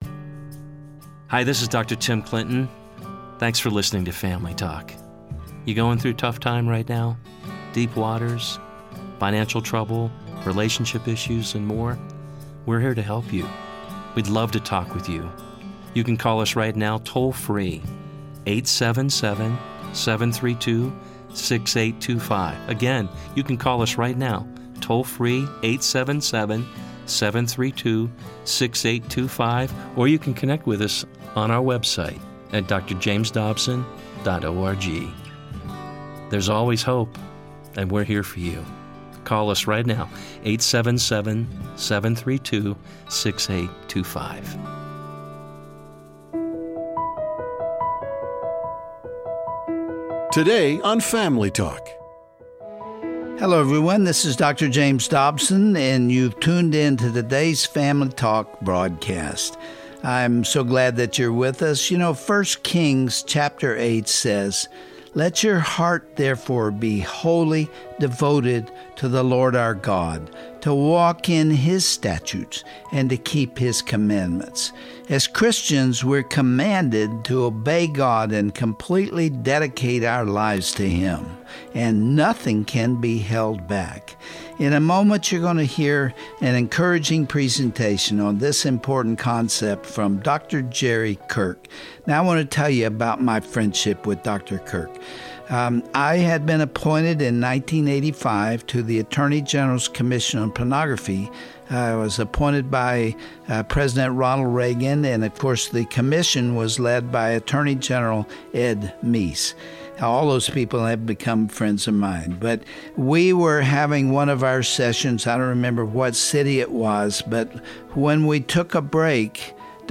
On this classic Family Talk broadcast